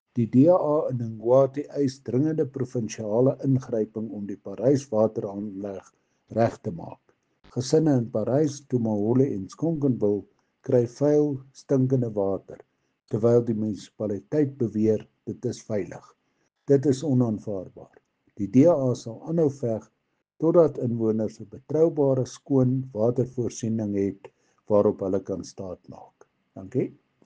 Afrikaans soundbites by Cllr Brendan Olivier and